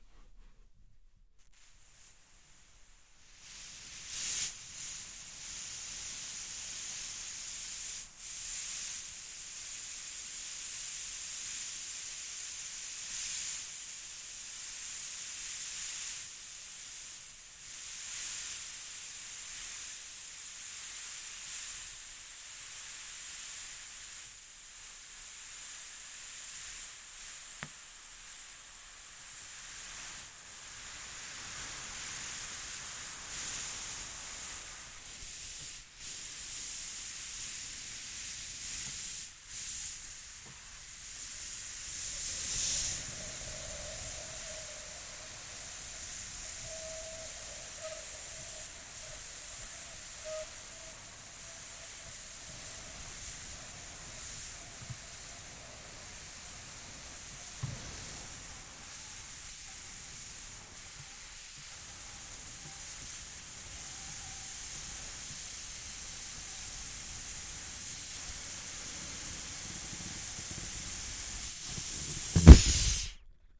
气球 " 气球放气长2
描述：录制为通过操纵气球创建的声音集合的一部分。
Tag: 折边 放气 气球 放屁